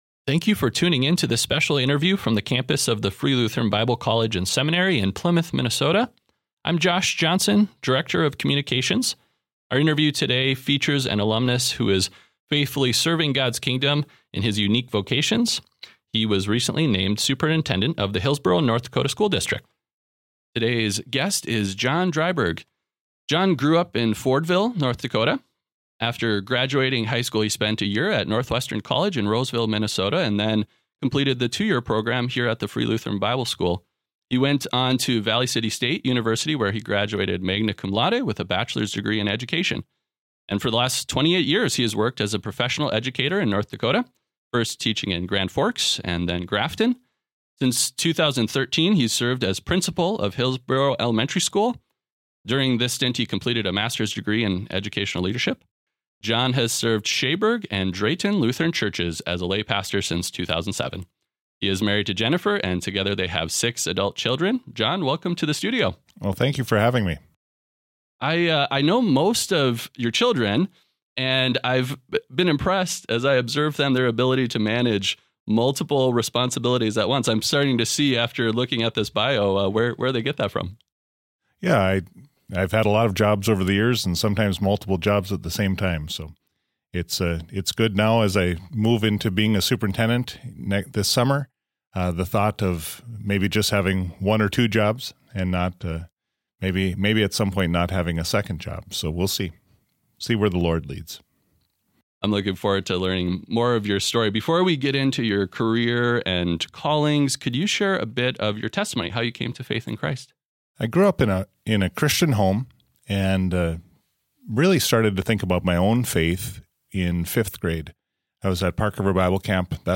The FLBC Podcast Alumni Interview